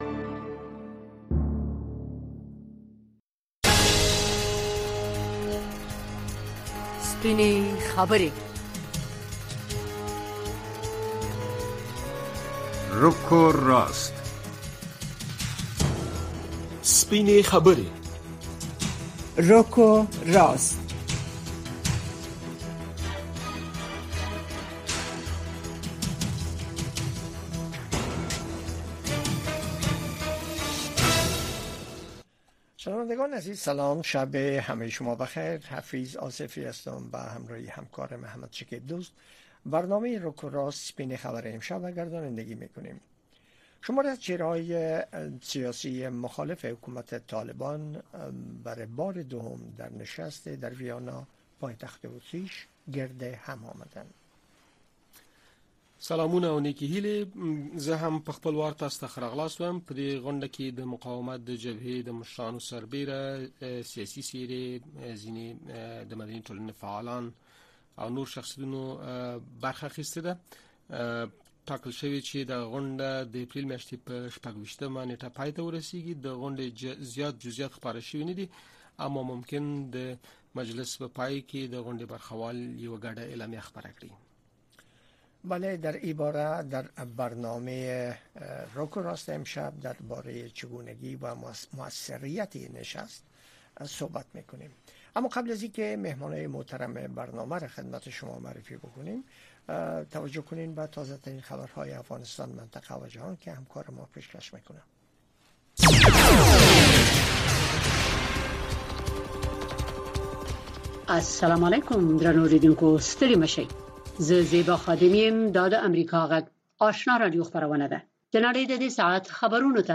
د ورځې په خبرونو اومسایلو د نظر د خاوندانو سپینې خبرې او د اوریدونکو نظرونه